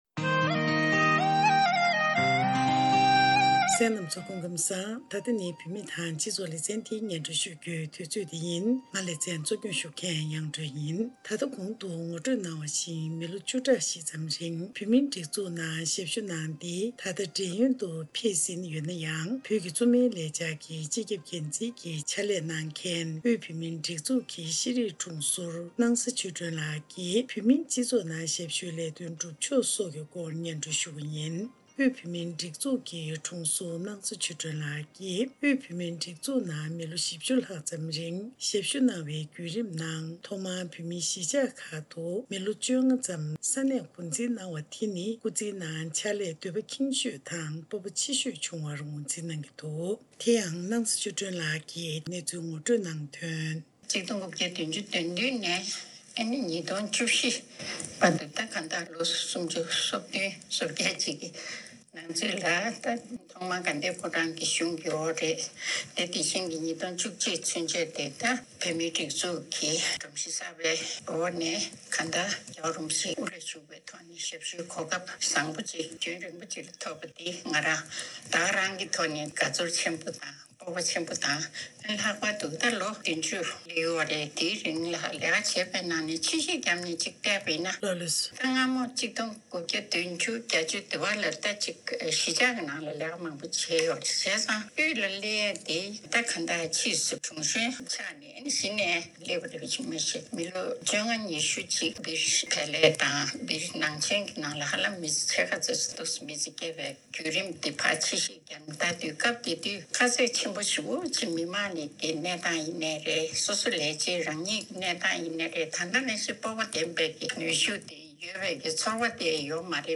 གནས་འདྲིའི་ལེ་ཚན་ནང།
ཞལ་པར་བརྒྱུད་ཐད་ཀར་གནས་འདྲི་ཞུས་པ་དེ་གཤམ་ལ་གསན་རོགས་གནང།